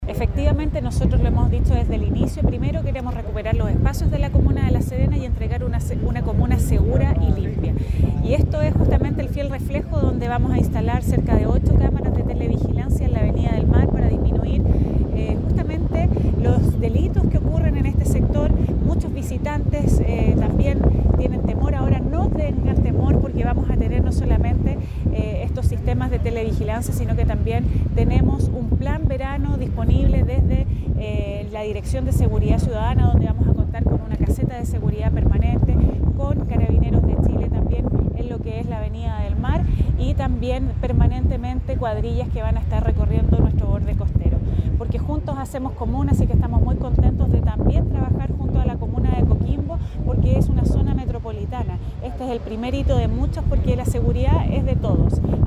ALCALDESA-LA-SERENA-DANIELA-NORAMBUENA.mp3